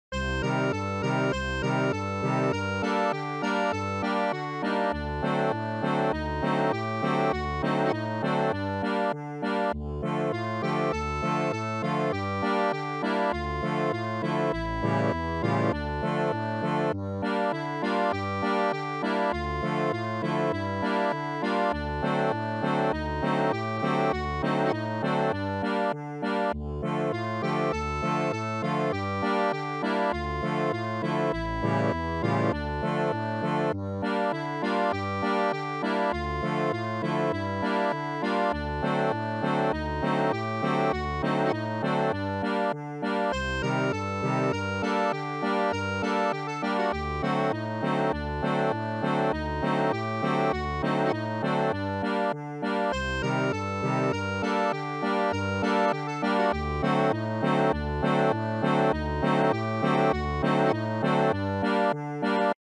Instrument: Accordéon Գործիք՝ Ակորդեոն